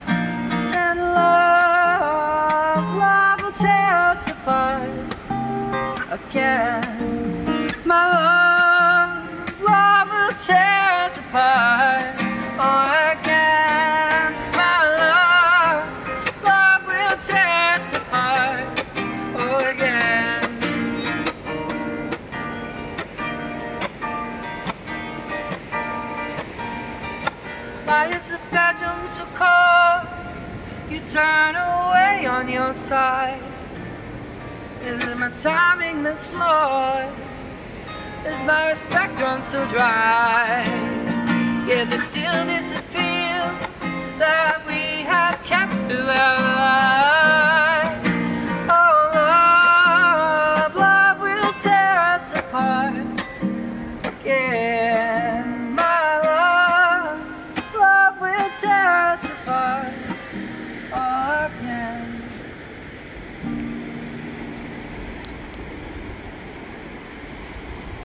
Vocals | Guitar | Looping | DJ